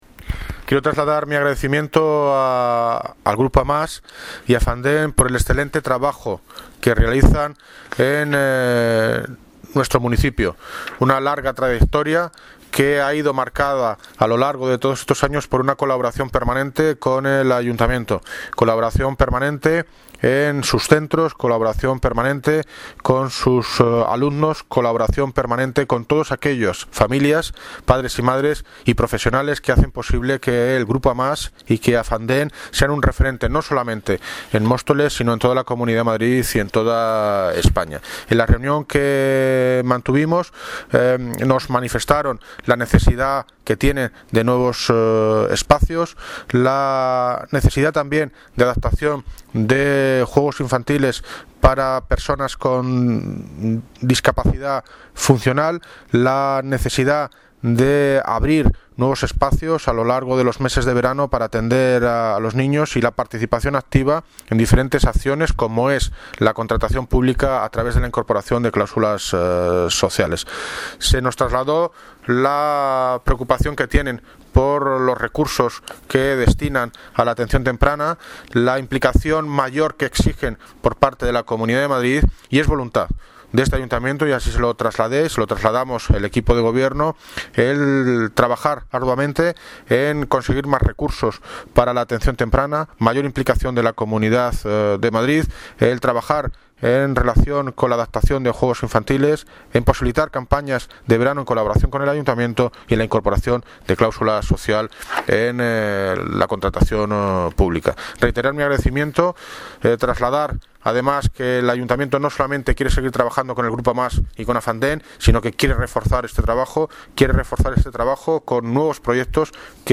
Audio - David Lucas (Alcalde de Móstoles) Sobre Reunión AMAS AFANDEM